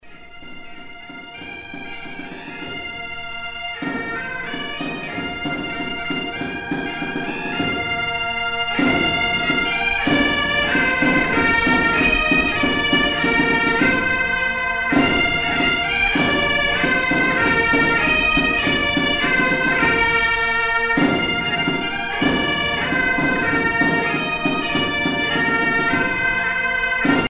danse : bal (Bretagne)
Pièce musicale éditée